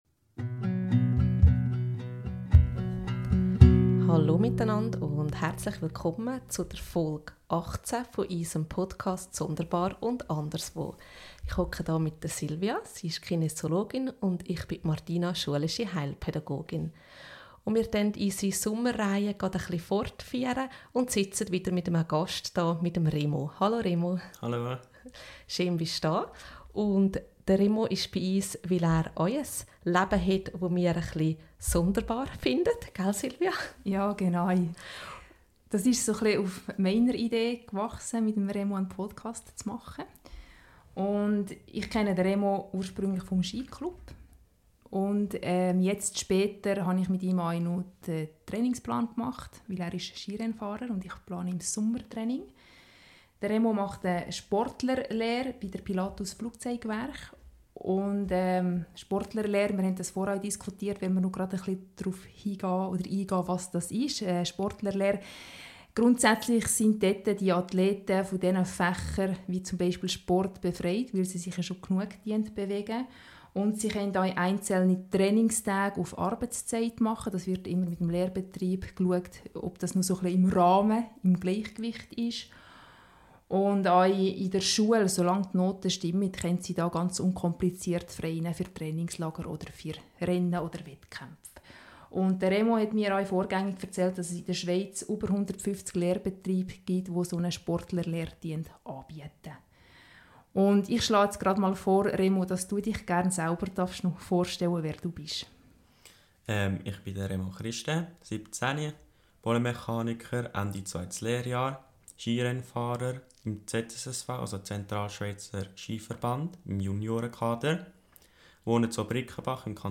Wir setzten unsere Sommerfolge mit einem weiteren Gast fort.